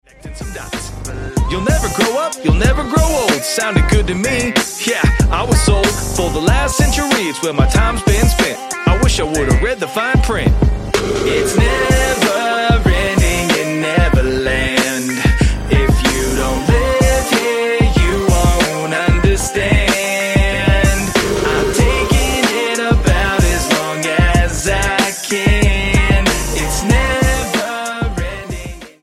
STYLE: Hip-Hop
Sung hooks and some clever production